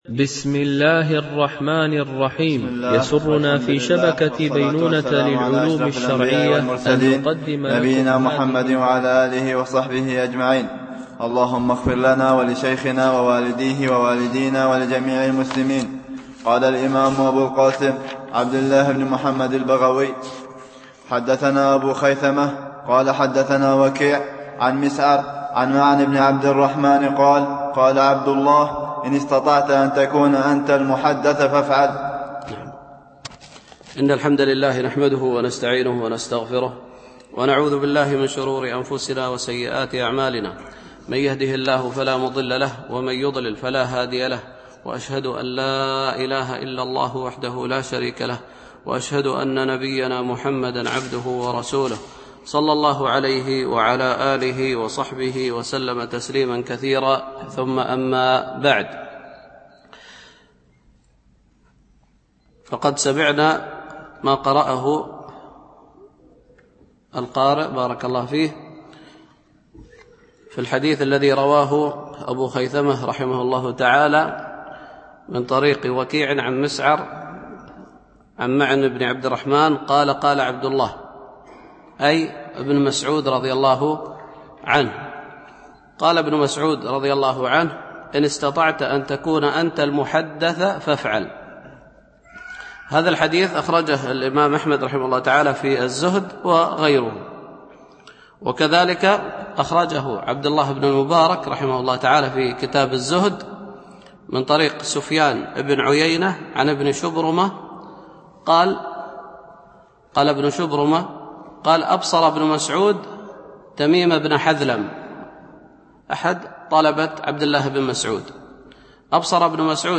شرح كتاب العلم لأبي خيثمة ـ الدرس 7 (الأثر 18- 19)